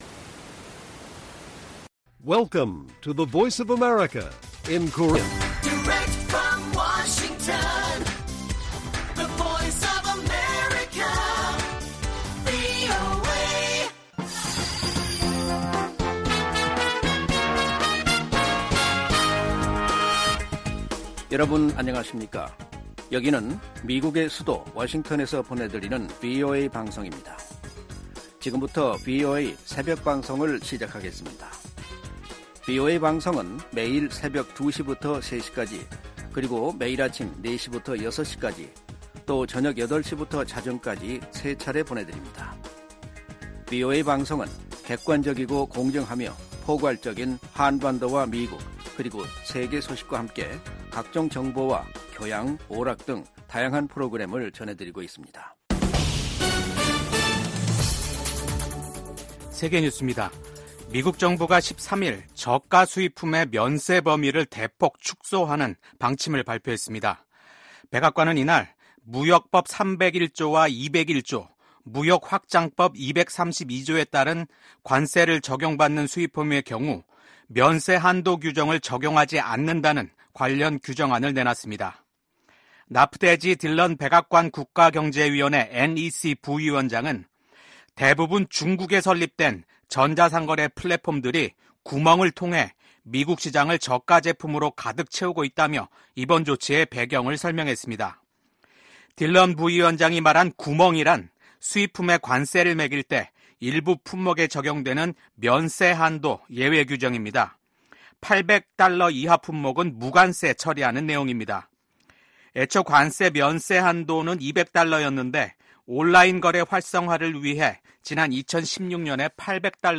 VOA 한국어 '출발 뉴스 쇼', 2024년 9월 14일 방송입니다. 북한이 핵탄두를 만드는 데 쓰이는 고농축 우라늄(HEU) 제조시설을 처음 공개했습니다. 미국과 리투아니아가 인도태평양 지역에 대한 고위급 대화를 개최하고 러시아의 북한제 탄도미사일 사용을 비판했습니다.